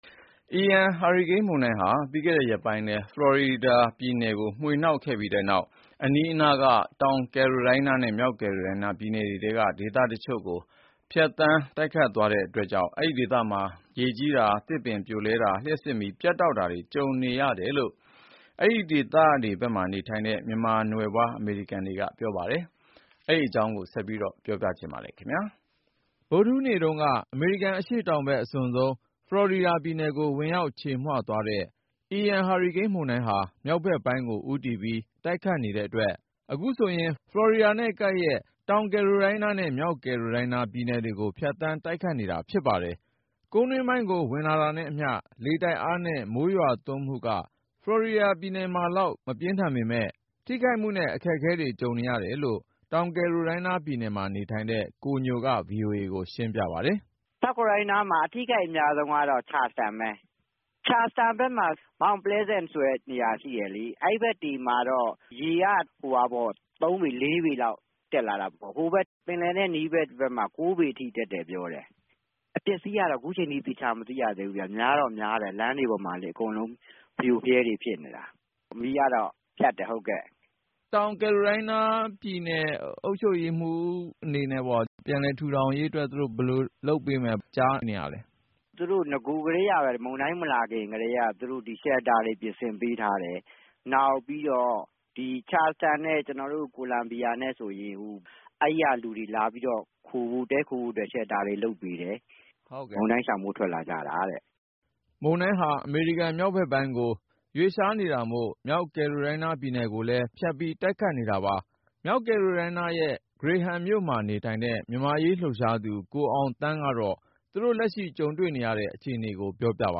IAN ဟာရီကိန်း မုန်တိုင်းဟာ ရေကြီးတာ၊ သစ်ပင်တွေပြိုလဲတာနဲ့ လျှပ်စစ်မီး ပြတ်တောက်တာတွေ တွေ့ကြုံခံစားရတယ်လို့ အဲဒီဖက်မှာ နေထိုင်တဲ့ မြန်မာနွယ်ဖွား အမေရိကန်တွေက ပြောဆိုကြပါတယ်။